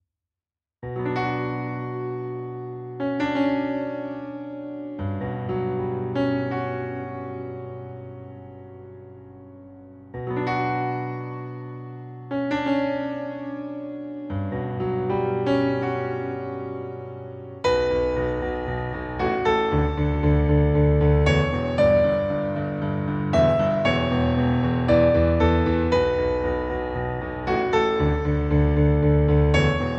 • 🎹 Instrument: Piano Solo
• 🎼 Key: B Minor
• 🎶 Genre: Rock